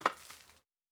Bat Throw Distant Impact.wav